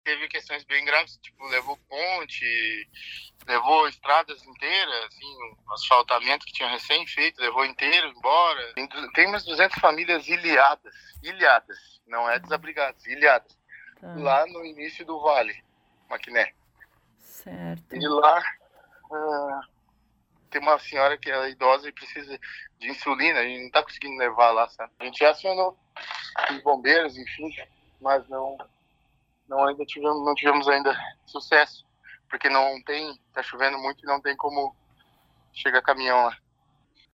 Em Maquiné, o trabalho da força-tarefa para minimizar os impactos do município enfrenta bastante dificuldade em função da chuva que ainda atinge a região. Além de 50 famílias desabrigadas, existem mais 200 famílias ilhadas, sem acesso nem mesmo por barco, conforme relata o prefeito João Carlos Bassani.